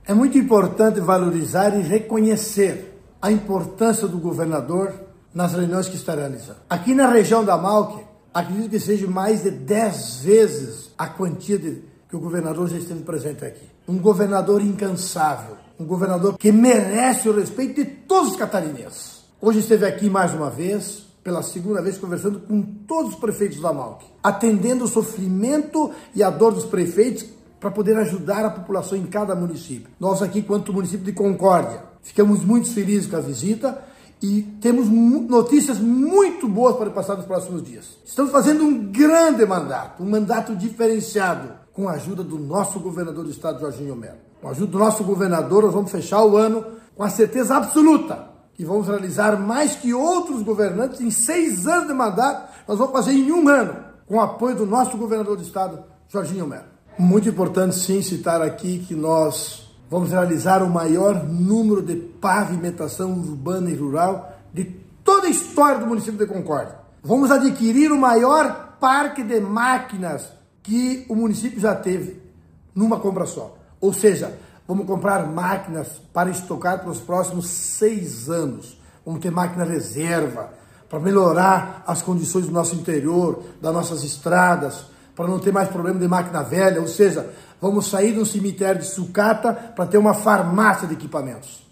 Após a conversa individual com o governador Jorginho Mello, o prefeito de Concórdia, Edilson Massocco, destaca pavimentação asfáltica, urbana e rural, além de aquisição de equipamentos agrícolas: